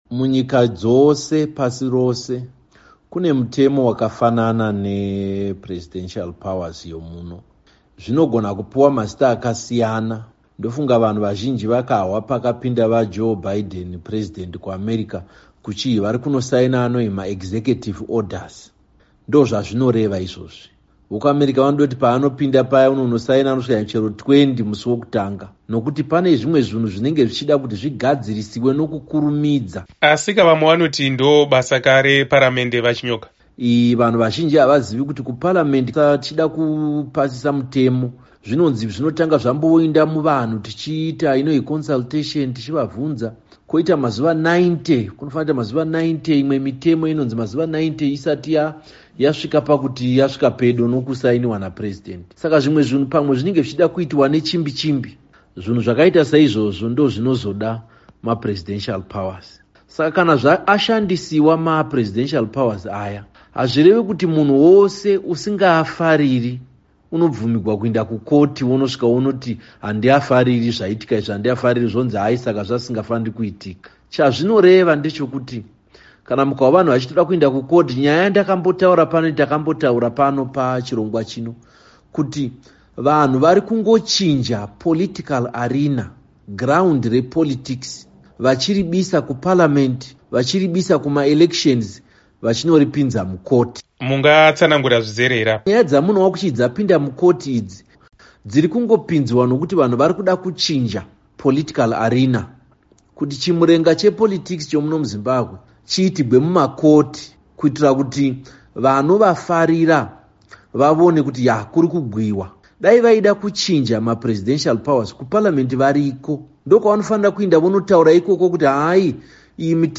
Hurukuro naVaTendai Biti